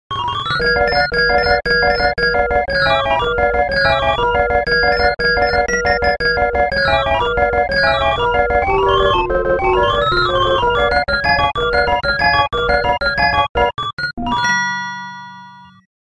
Waltz in 26edo sound effects free download